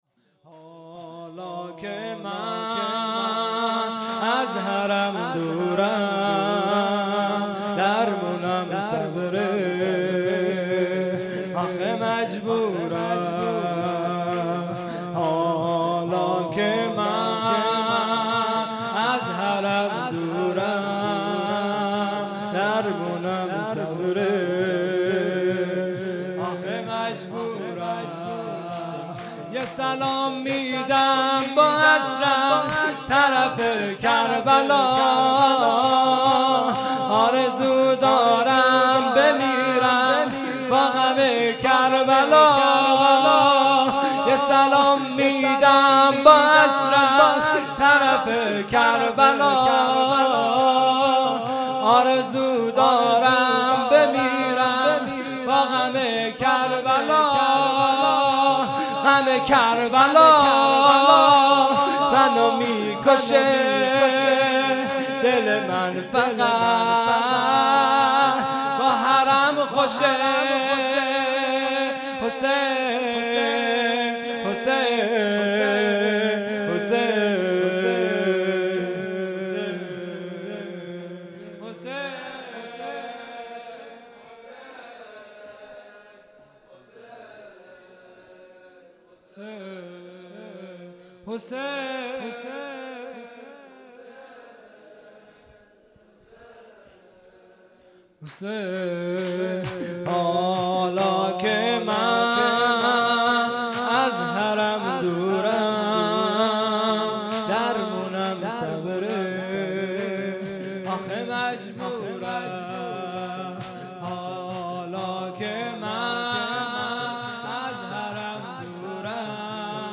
شور | حالا که من از حرم دورم | 25 فروردین 1401
جلسۀ هفتگی | مناجات ماه رمضان | پنج شنبه 25 فروردین 1401